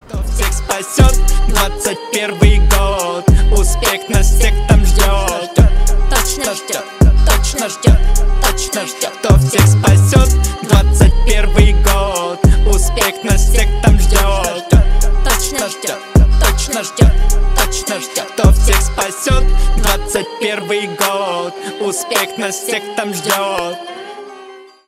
Рэп и Хип Хоп
пародия